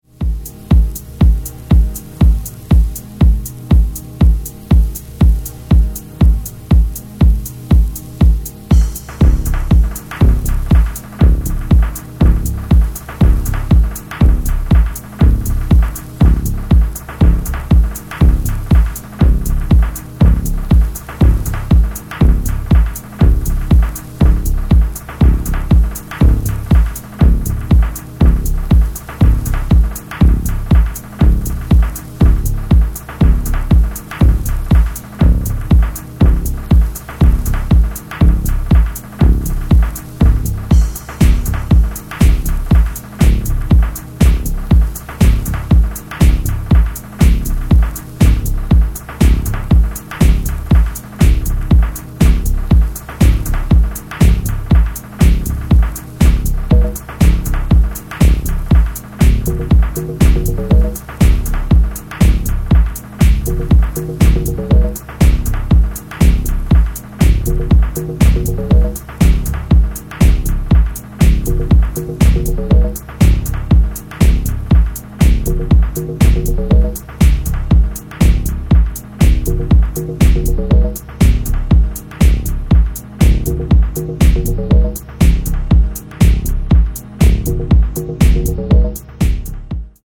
Electronix Dub Ambient